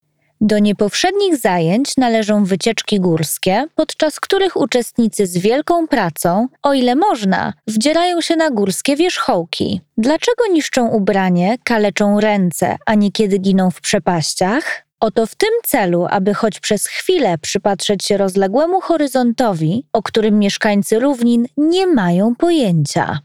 Narration
YoungProfessionalFriendlyWarmPersonableBrightEnergeticUpbeat
All our voice actors record in their professional broadcast-quality home studios using high-end microphones.